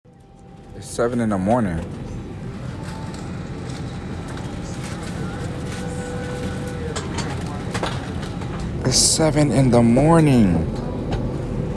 Insert Spiderman web sound effects. sound effects free download